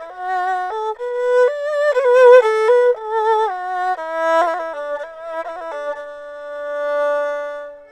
Oriental Violin
orientalviolin.wav